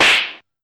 slap.wav